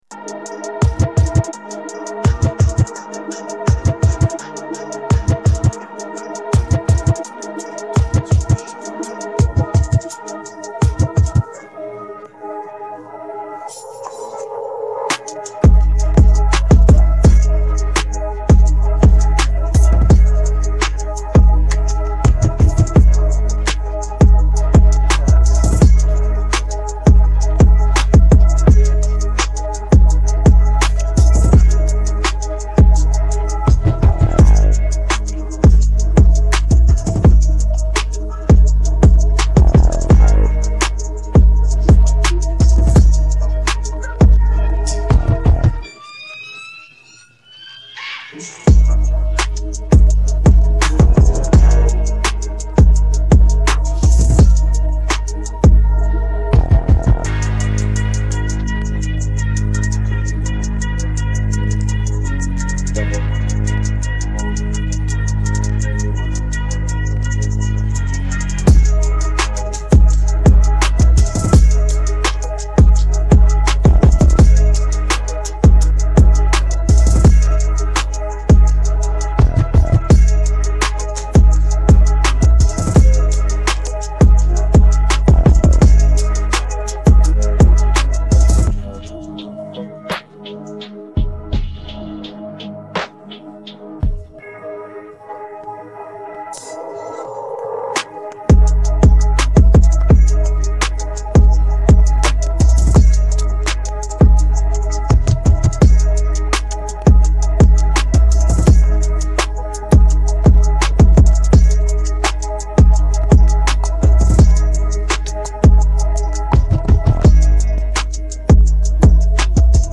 Musica di sottofondo